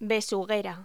Locución: Besuguera